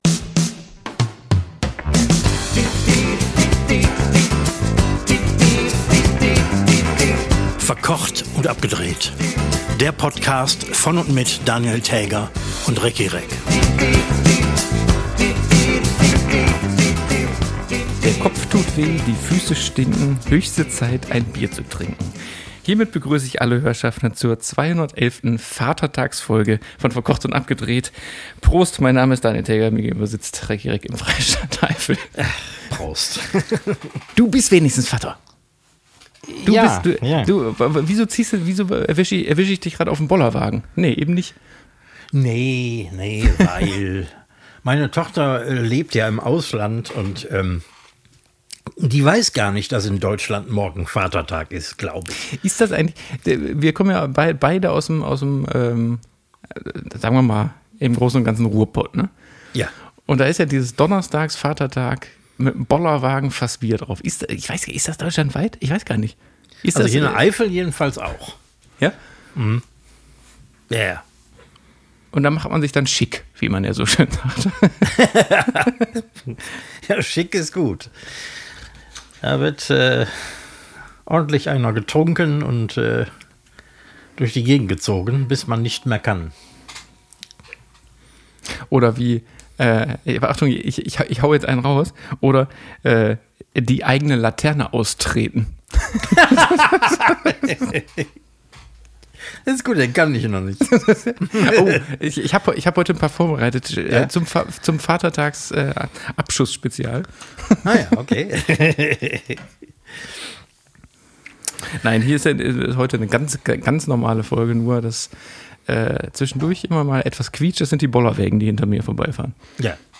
Und im Hintergrund hört man leise von zwei Zuhörschaffenden: Prostataaaa….